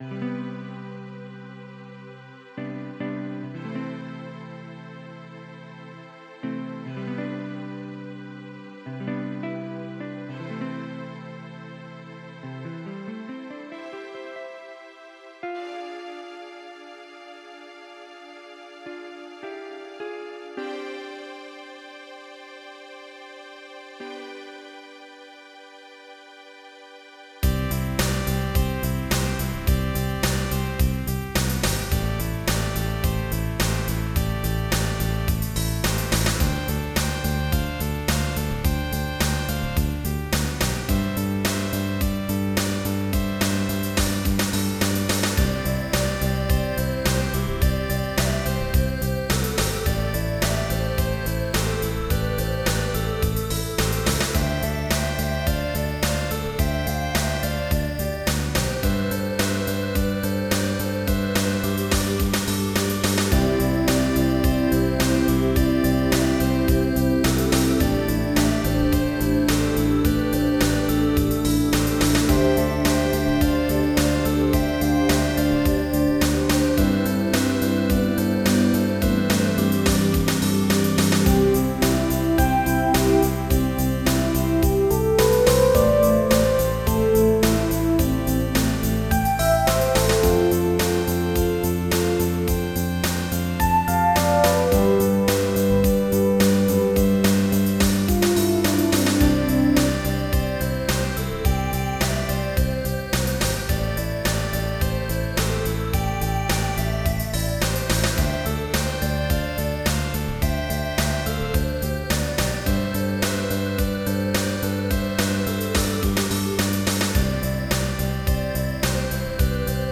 Pop
MIDI Music File
Type General MIDI